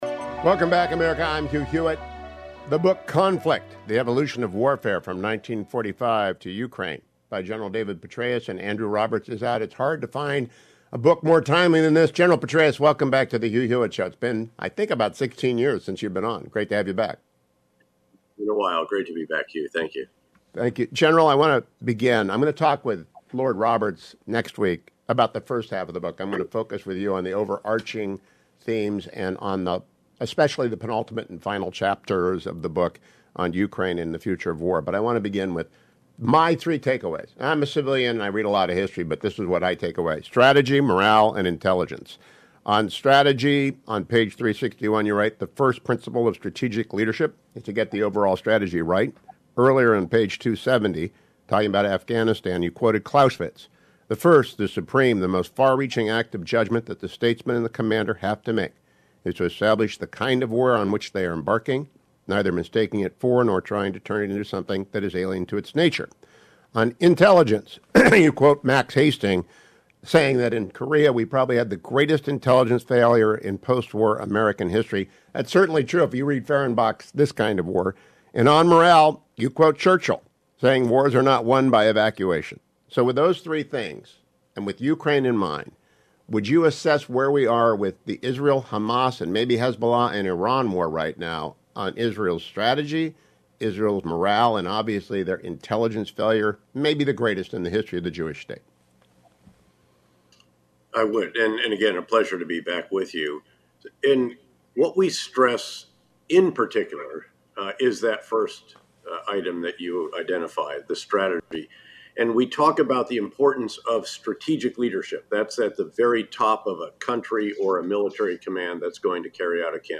The interview ill play on Friday’s radio show but for those who are eager for his early assessment of Israel’s war aims and dilemmas, here is the interview: